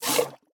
Minecraft Version Minecraft Version snapshot Latest Release | Latest Snapshot snapshot / assets / minecraft / sounds / mob / mooshroom / milk2.ogg Compare With Compare With Latest Release | Latest Snapshot
milk2.ogg